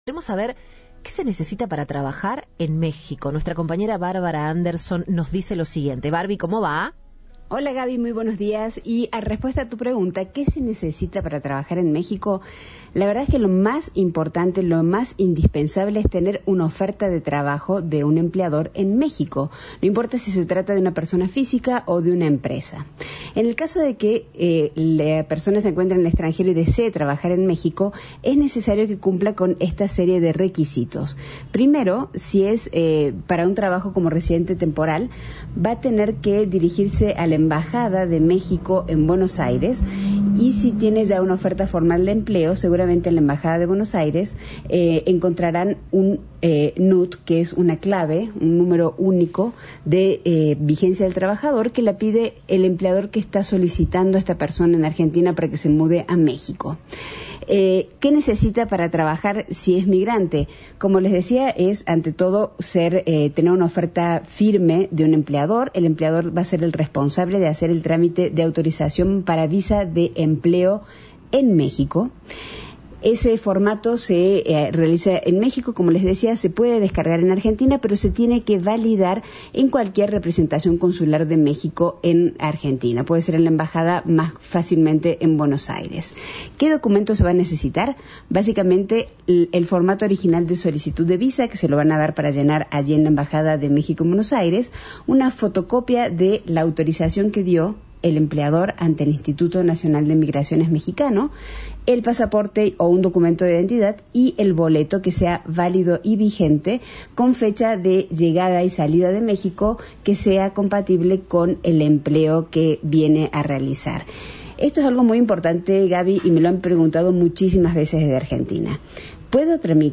Los corresponsales de Cadena 3 explicaron qué se necesita para trabajar en algunos países del exterior, luego de que se supiera que creció la demanda de visas al exterior.